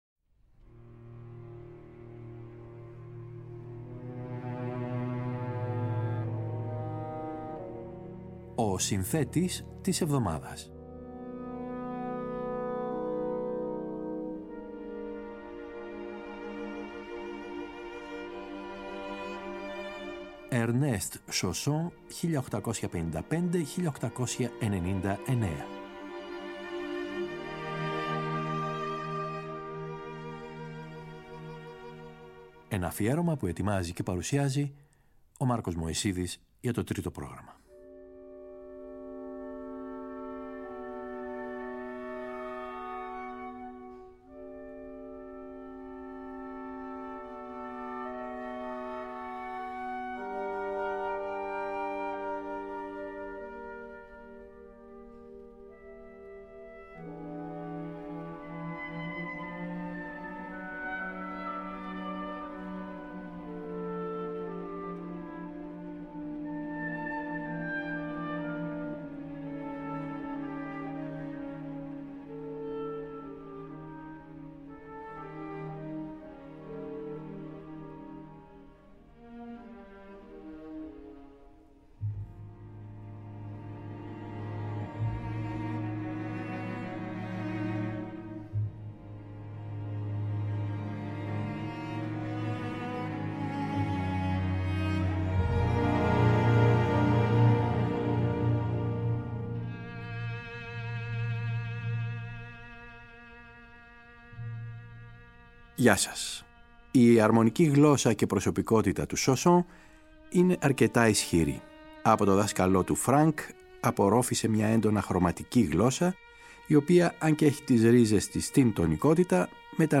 Ο Ernest Chausson θεωρείται πρόδρομος του εξπρεσιονισμού και ανέπτυξε ένα ύφος που χαρακτηρίζεται από χάρη και λεπτότητα.
Η μουσική του χωρίς να έχει τις μεγάλες ιδέες του Beethoven, του Brahms, του Mahler ή του R.Strauss, είναι παθιασμένη και ερωτική, αμφιταλαντευόμενη μεταξύ του Βαγκνερικού ρομαντισμού και του αισθησιασμού του Debussy.